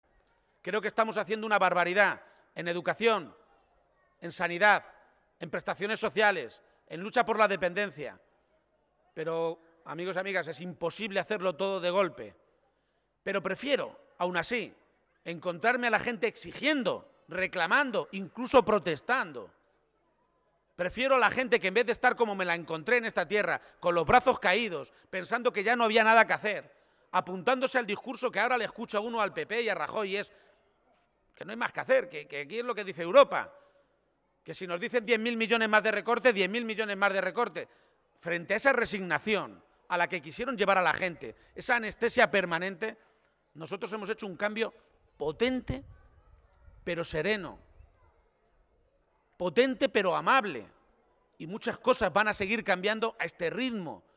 En un acto público celebrado en la localidad toledana de Yuncos, Emiliano García-Page aseguraba que “soy presidente de Castilla-La Mancha por el voto tremendo y de coraje de mucha gente que votó a pesar de los medios de comunicación, de las encuestas, de la manipulación y también de dos leyes tramposas electorales que se hicieron en Castilla-La Mancha”, por eso, añadía, “quiero apelar al coraje y también a la humildad”.
Cortes de audio de la rueda de prensa